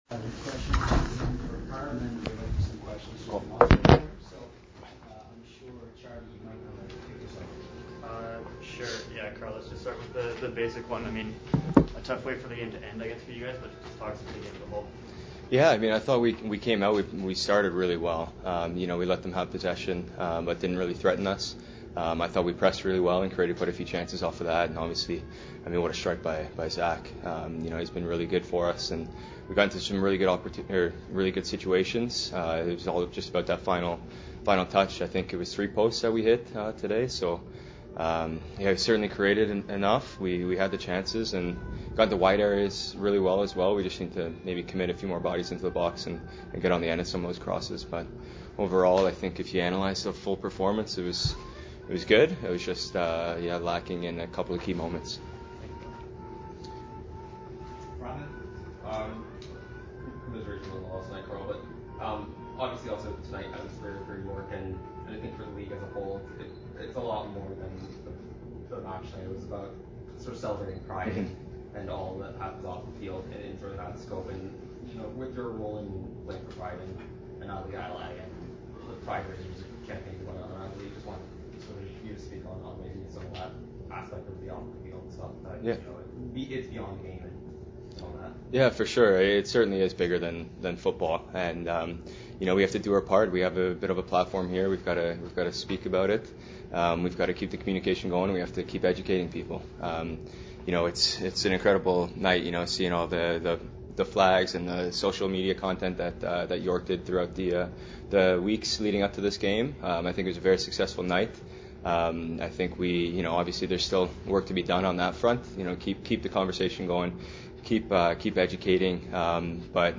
All the audio from the post game press conference after the CPL (regular season game) game